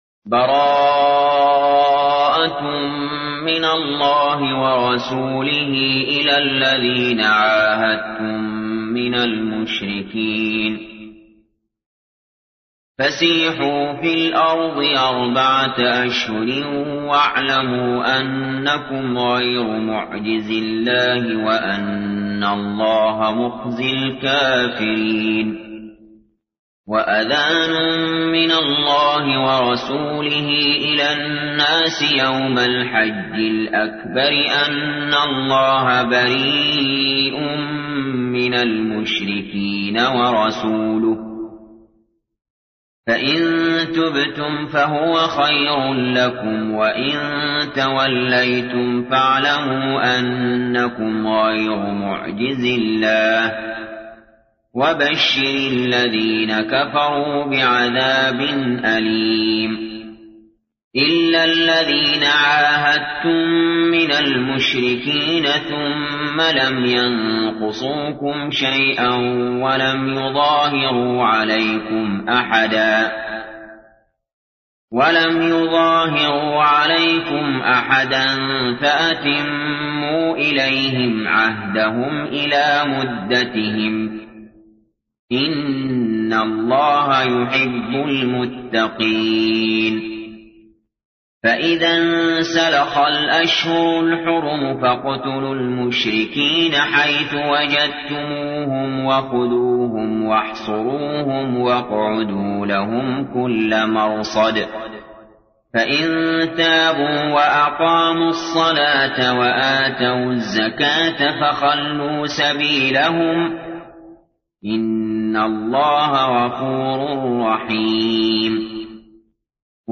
تحميل : 9. سورة التوبة / القارئ علي جابر / القرآن الكريم / موقع يا حسين